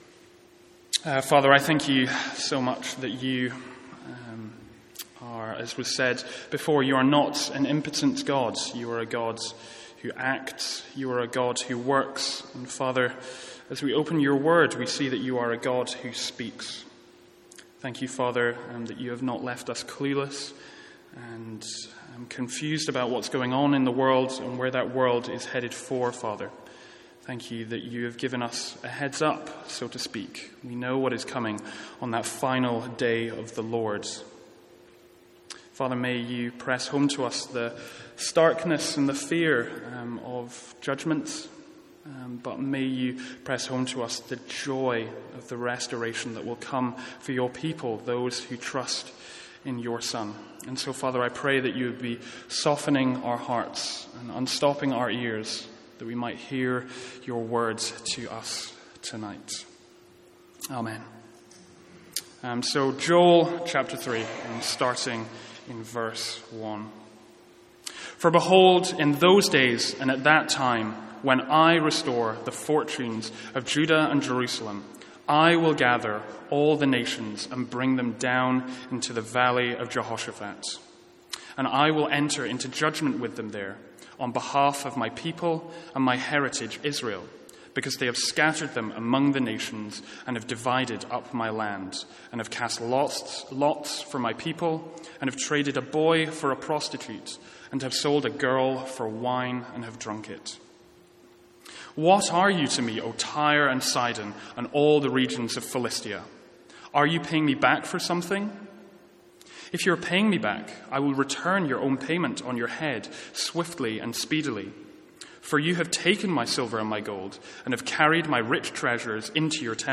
From our evening service on Joel.